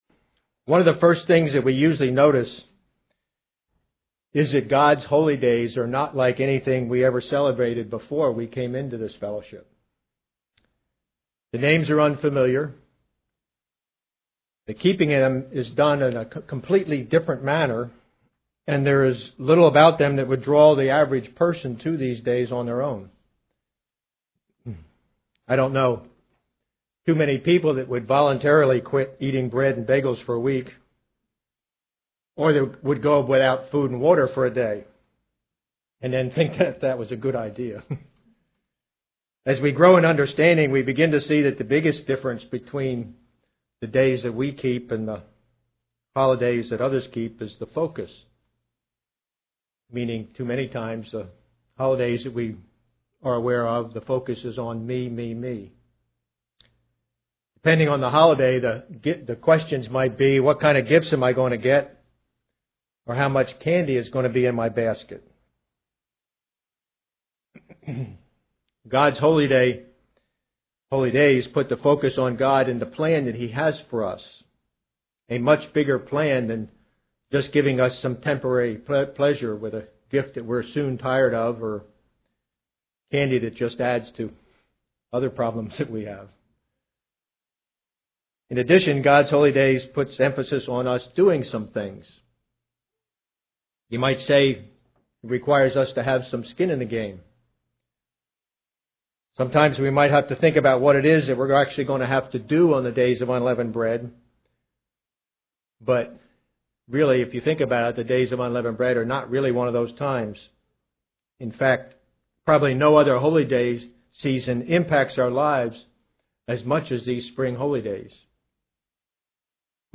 Given in Ft. Lauderdale, FL
UCG Sermon Studying the bible?